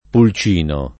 pul©&no] s. m. — con tronc., il pulcin della Minerva, nome scherz. del piccolo elefante di G. L. Bernini che sostiene l’obelisco in piazza di Santa Maria sopra Minerva a Roma — un es. di tronc.